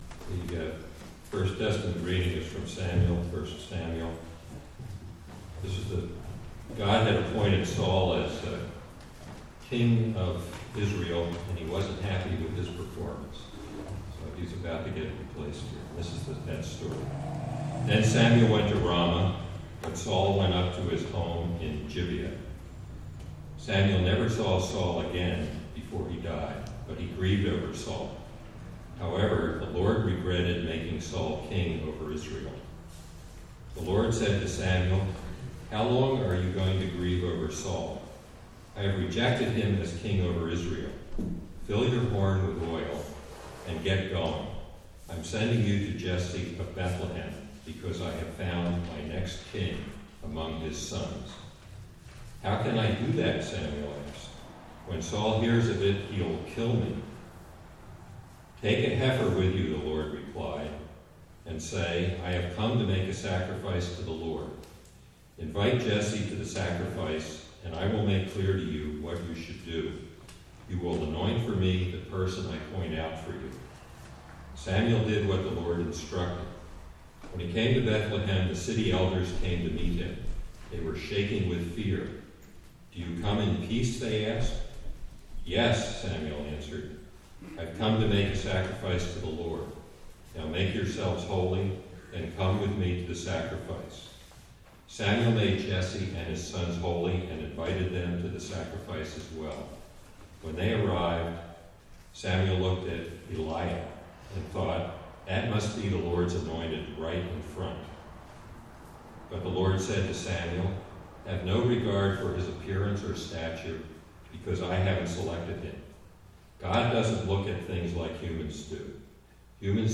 Sermon Delivered at: The United Church of Underhill (UCC and UMC)